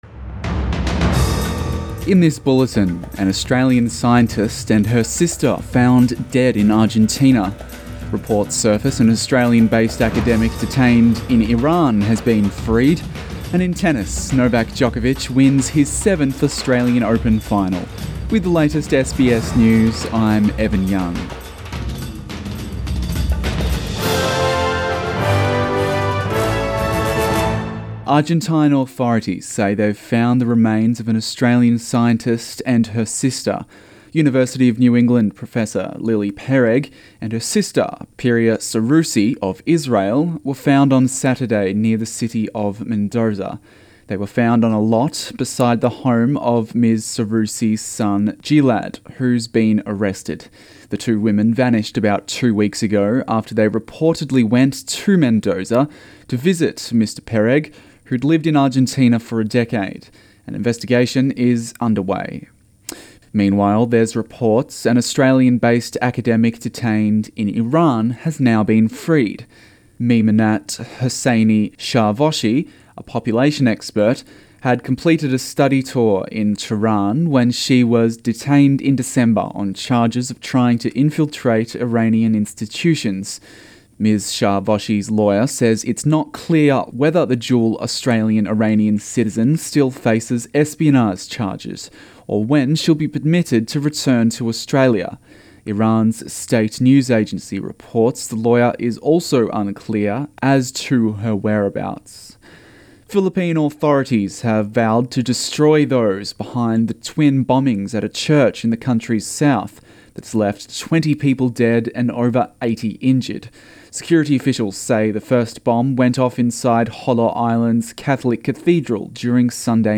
AM bulletin 28 January